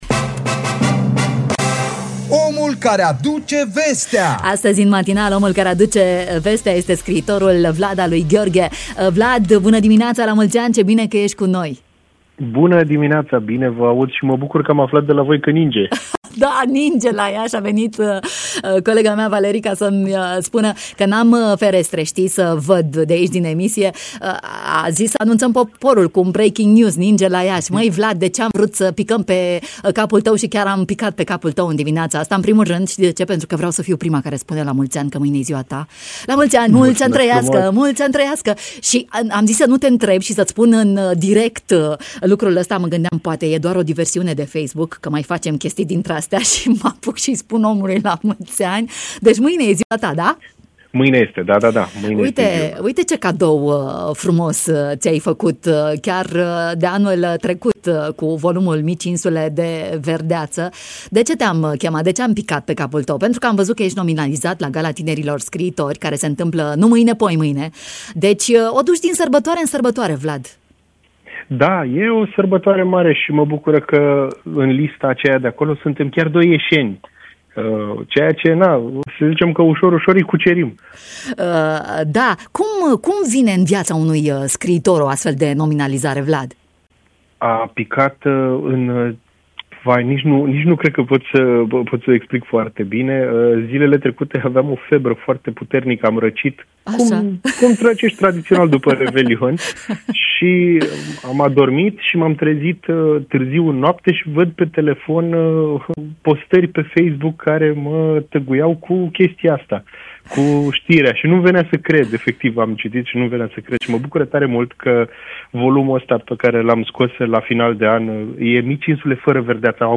în direct la Radio România Iași